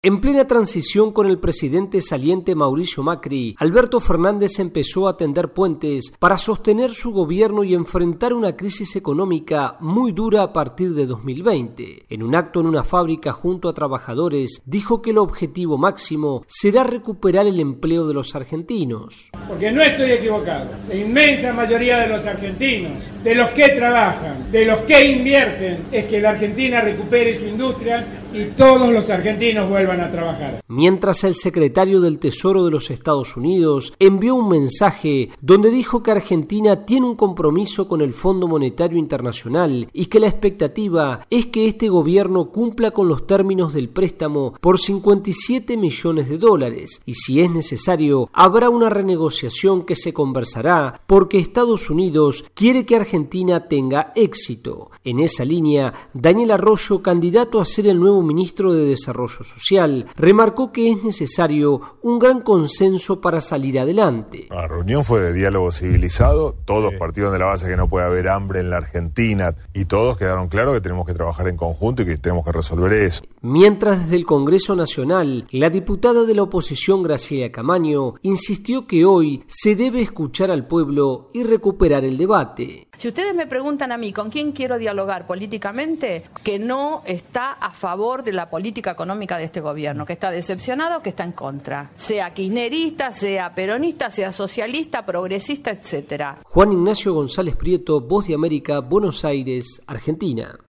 VOA: Informe de Argentina